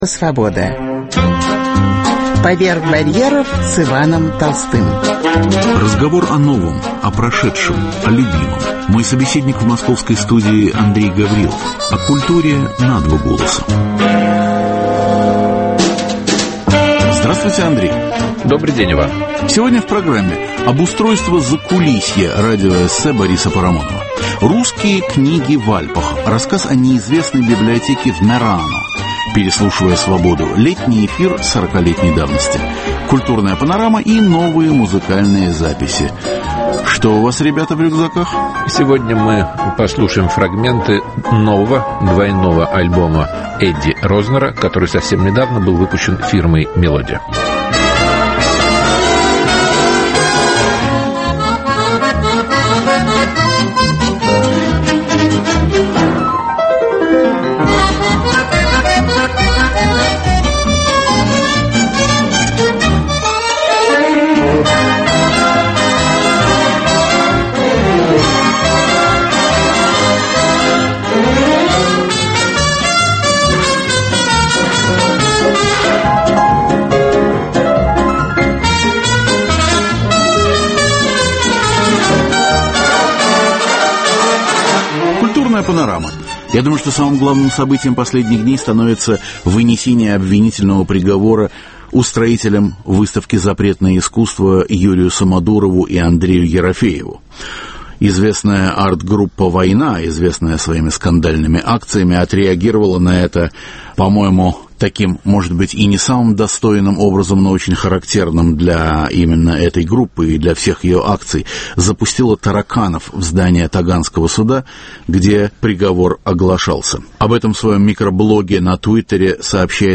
Обустройство закулисья – радиоэссе Бориса Парамонова. Русские книги в Альпах – рассказа о неизвестной библиотеке в Мерано. Переслушивая Свободу: поэма Юлия Даниэля «А в это время».